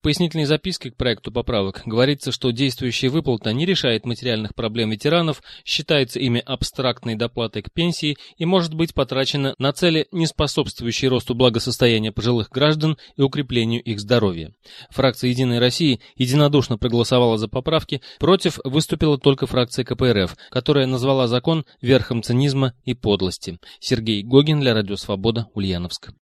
Из Ульяновска передает корреспондент Радио Свобода